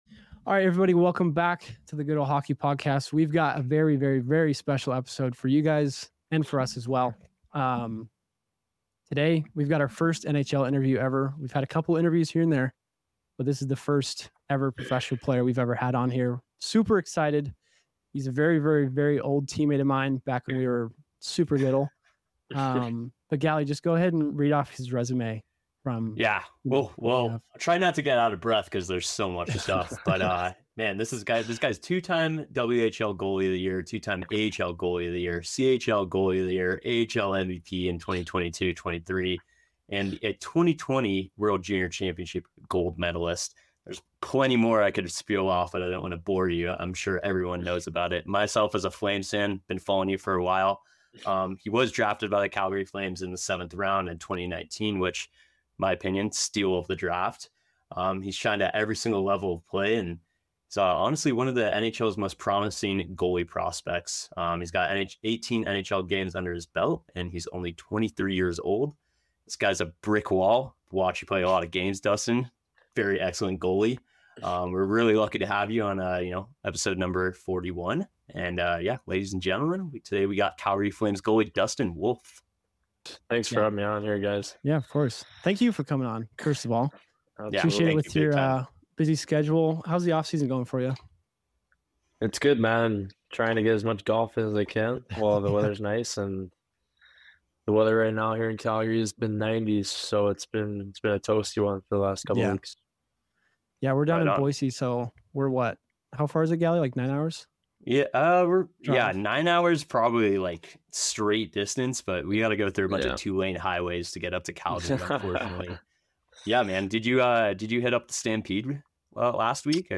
In this special episode of the Good Ol Hockey Podcast, we feature an exclusive interview with Calgary Flames Goalie Dustin Wolf. Join us as Dustin shares his inspiring journey from playing childhood hockey in California to becoming an NHL starting goalie. We delve into his major achievements, including his experiences in the WHL, AHL, winning a gold medal at the World Junior Championships, and his excitement for the upcoming NHL season.